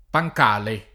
pancale [ pa j k # le ] s. m.